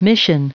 Prononciation du mot mission en anglais (fichier audio)
Prononciation du mot : mission